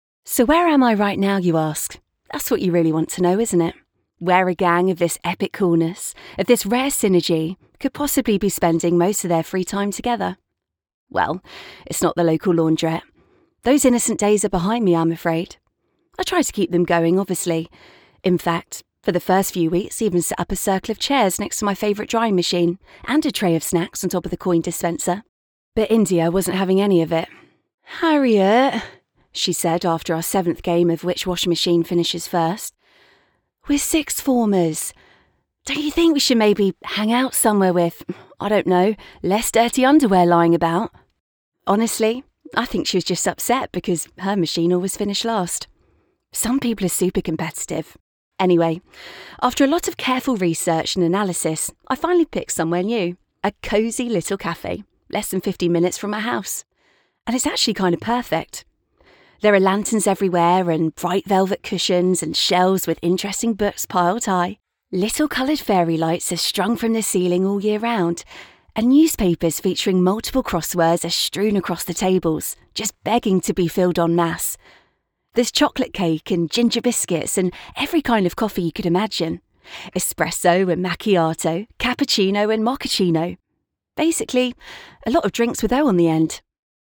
Native Voice-Samples
With over 7 years experience as a voiceover I have had the pleasure of voicing for multiple brands recording from my broadcast quality home studio or in-person sessions.
Junger Erwachsener
Mezzosopran